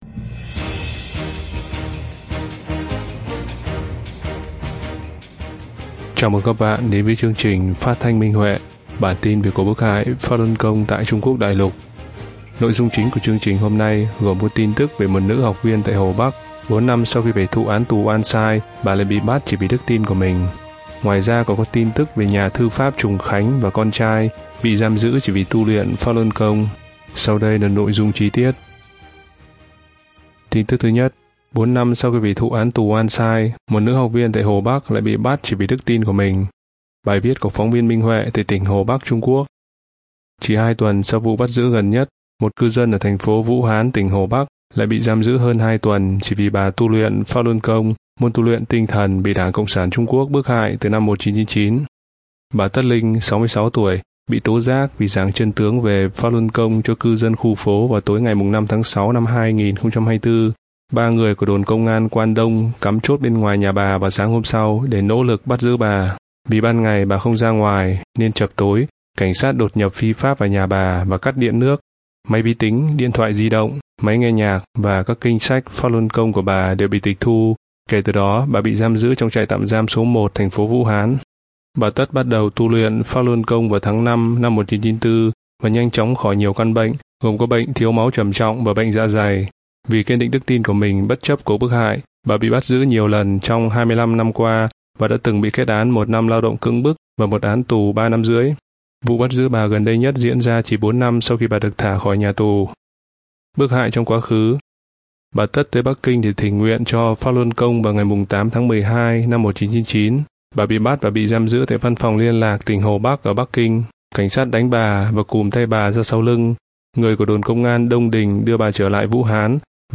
Chương trình phát thanh số 115: Tin tức Pháp Luân Đại Pháp tại Đại Lục – Ngày 27/06/2024